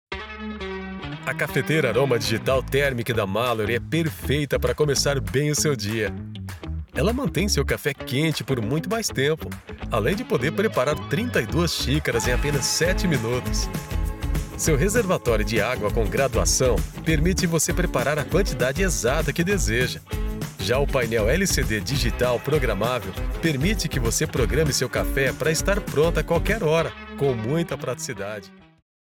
Brazilian voiceover, portuguese voiceover, brazilian voice actor, brazilian voice talent, brazilian portuguese voiceover, brazilian portuguese voice talent, brazilian portuguese voice actor, brazilian male voiceover, portuguese male voiceover, brazilian elearning voice, brazilian voice artist, brazilian portuguese
Sprechprobe: Sonstiges (Muttersprache):
Commercial internet _Portuguese.mp3